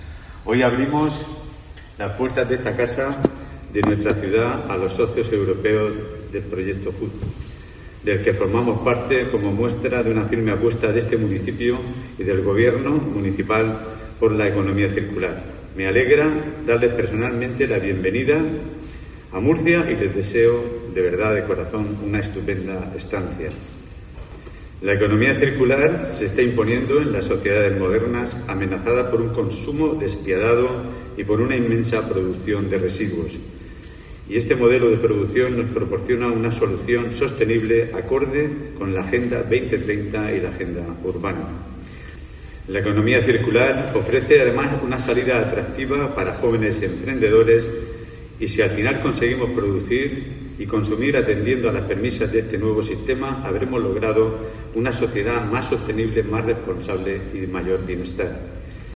José Antonio Serrano, alcalde de Murcia